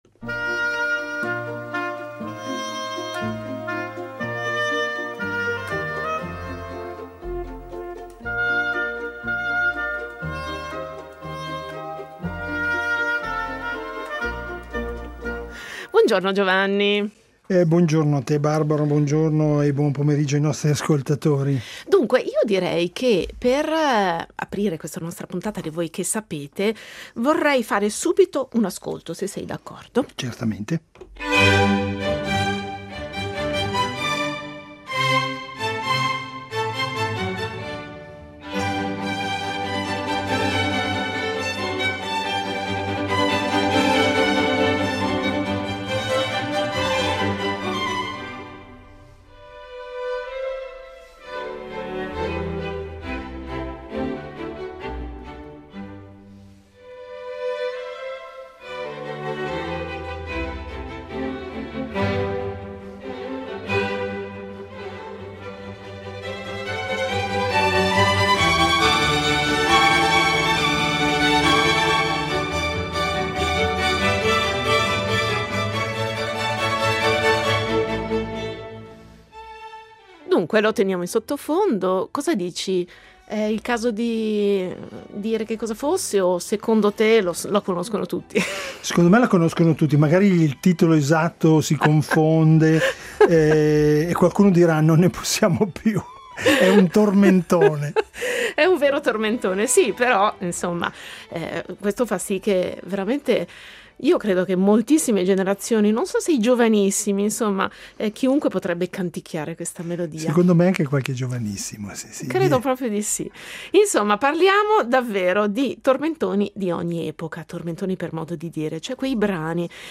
Ne parliamo con due esperti